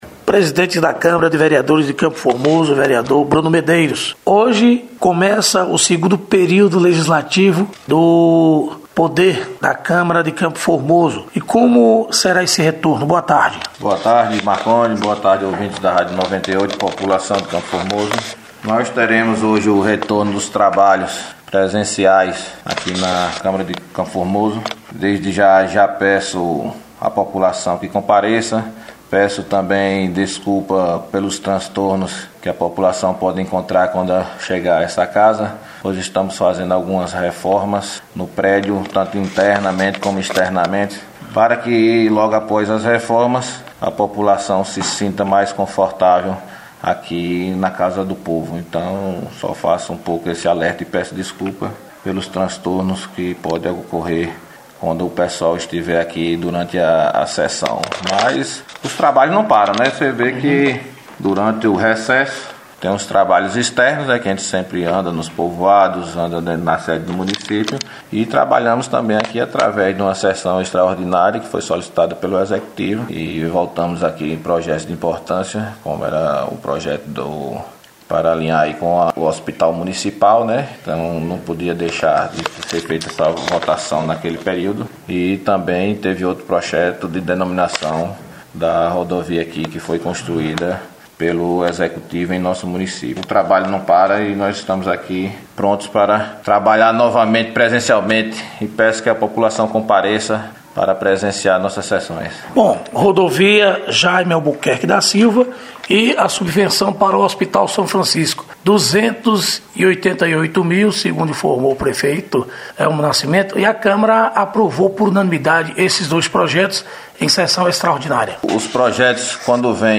Presidente do Legislativo Bruno Medeiros, falando sobre o retorno dos trabalhos legislativos da Câmara municipal de CFormoso